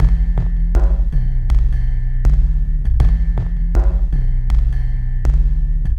Downtempo 10.wav